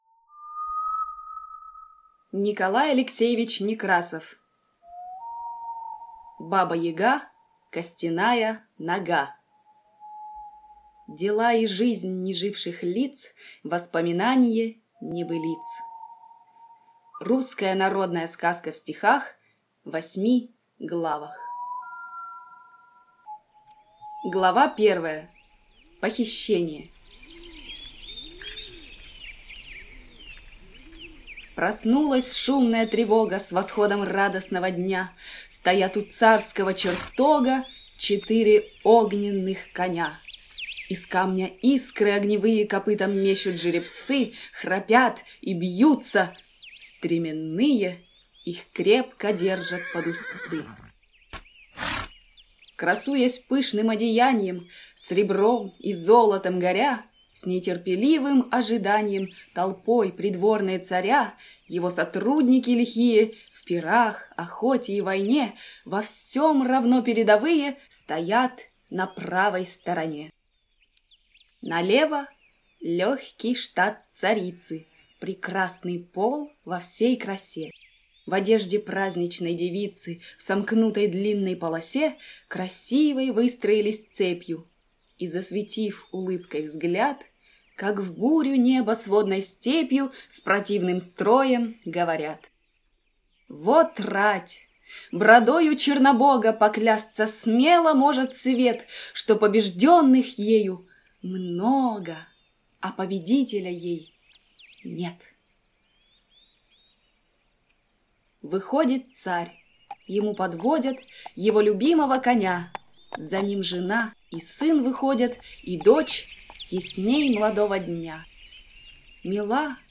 Аудиокнига Баба-Яга, Костяная Нога. Русская народная сказка в стихах.